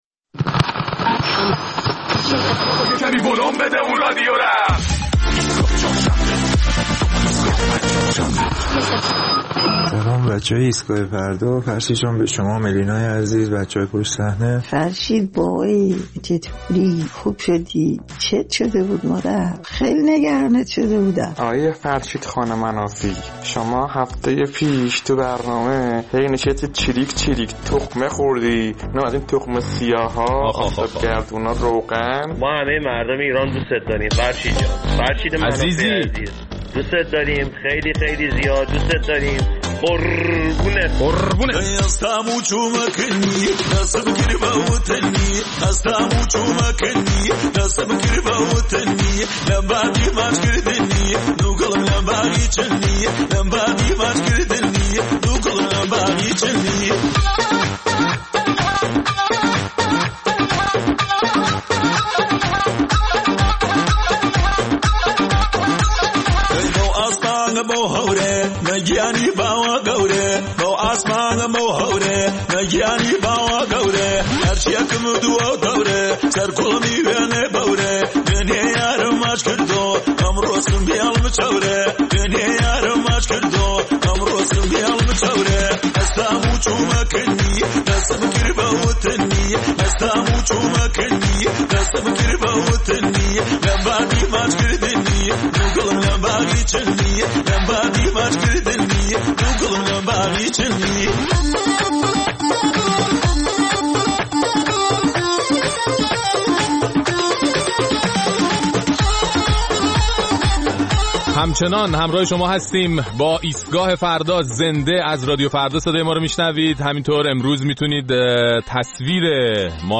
در این برنامه نظرات شنوندگان ایستگاه فردا را در مورد رویکرد جدید گشت ارشاد در رابطه با برخورد با مردان و زنان و کمپین حجاب بی‌ حجاب می‌شنویم.